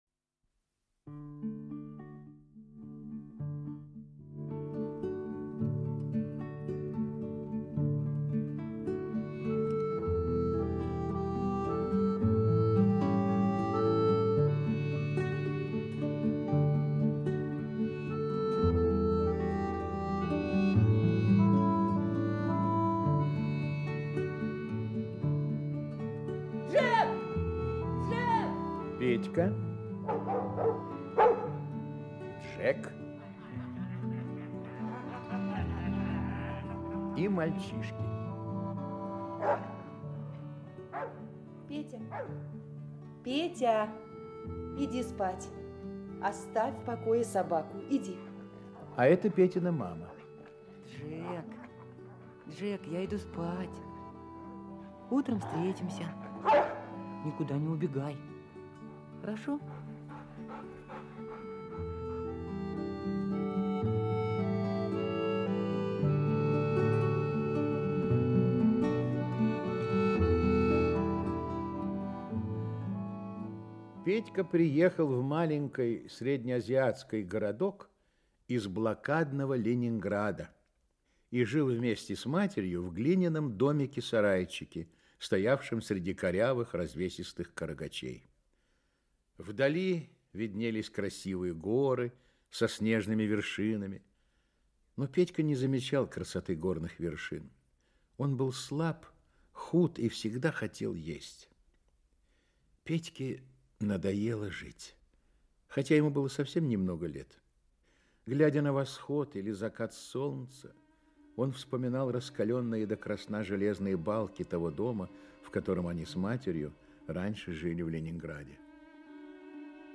Аудиокнига Петька, Джек и мальчишки | Библиотека аудиокниг
Aудиокнига Петька, Джек и мальчишки Автор Виктор Конецкий Читает аудиокнигу Актерский коллектив.